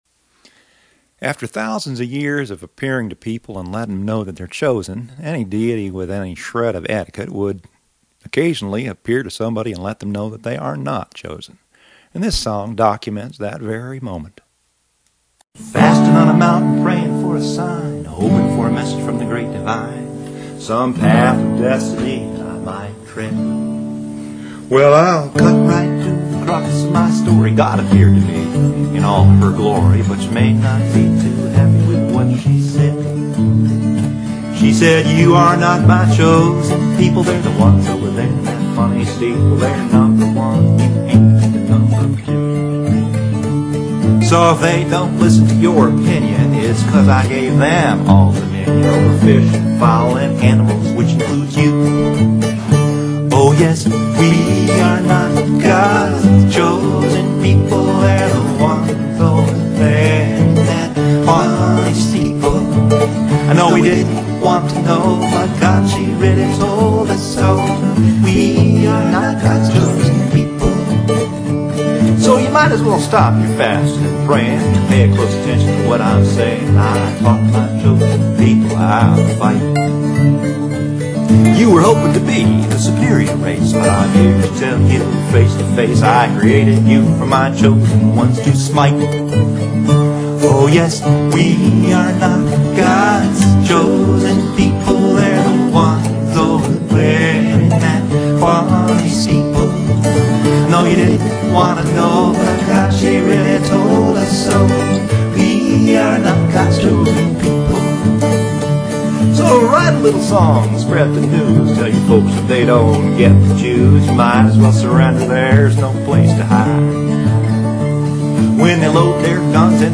[It's a casual living room recording with vocal, octave dulcimer and bass.
Here's one with cello, octave dulci and guitar
a rehearsal recording in an Olympus dictaphone gizmo
cheery bluegrass feel